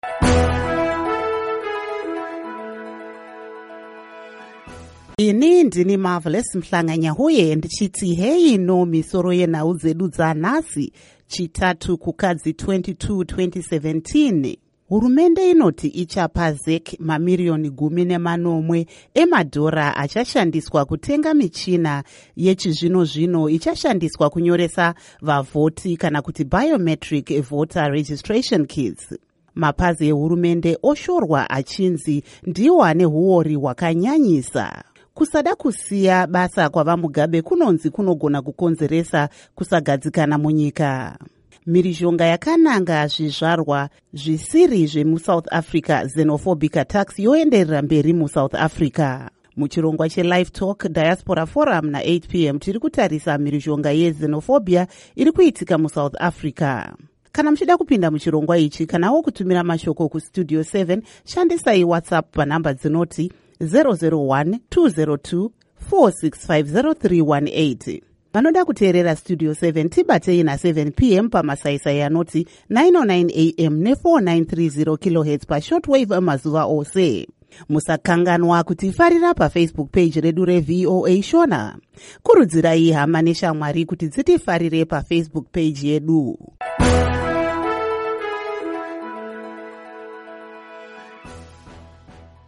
Misoro Yenhau Dzanhasi Chitatu, Kukadzi 22, 2017